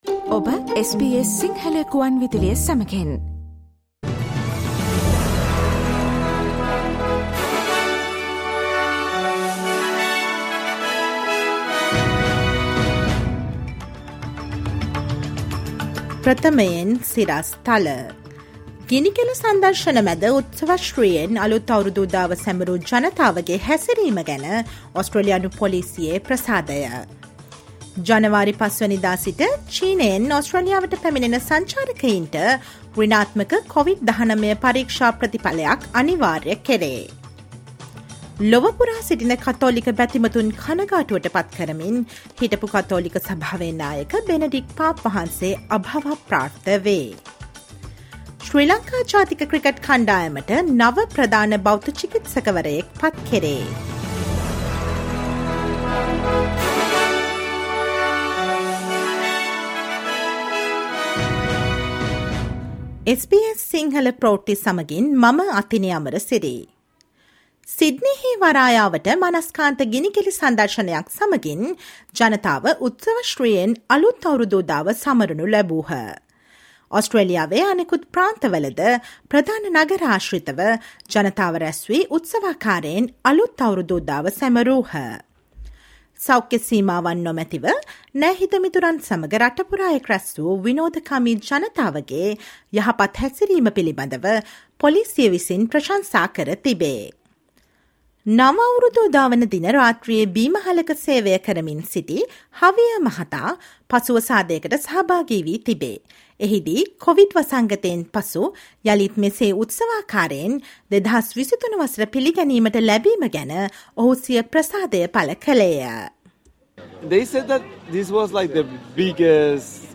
Police around Australia praised revellers for good behaviour in NYE celebrations: SBS Sinhala news on 02 Jan